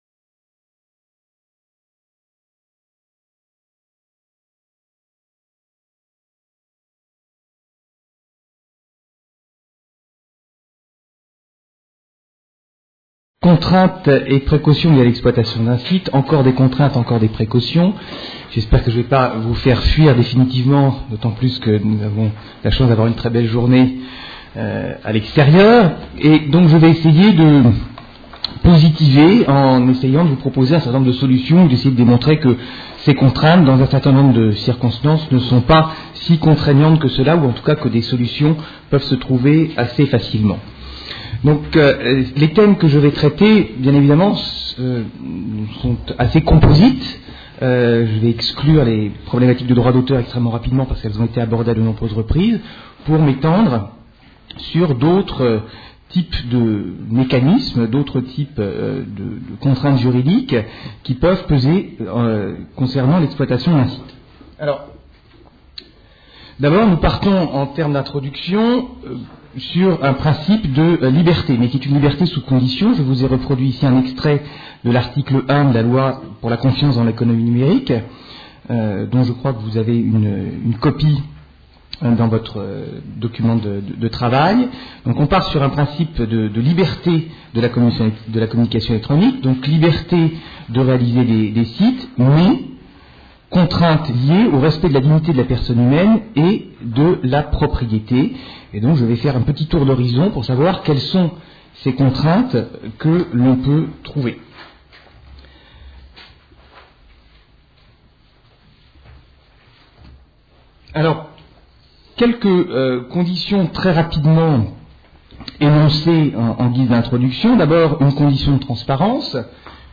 Table ronde
Questions de la salle et réponses des intervenants